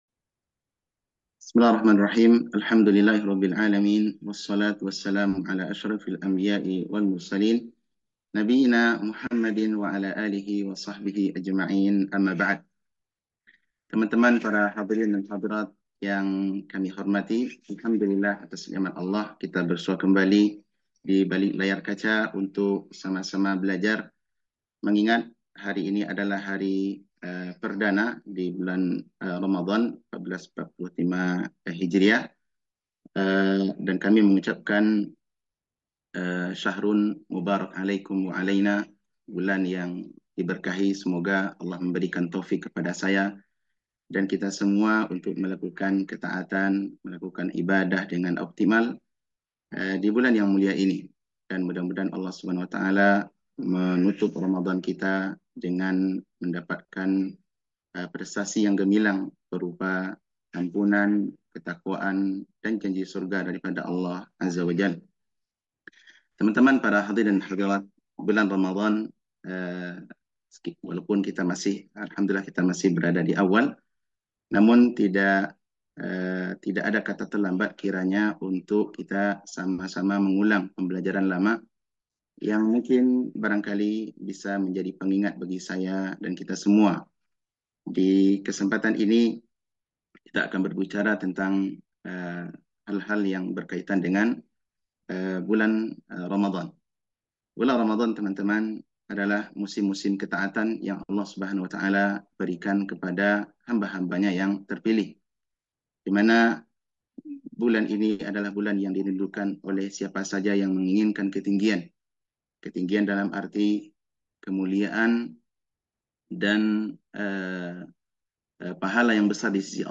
Kajian Online 1 – Daurah Ramadhan 1445H Wakra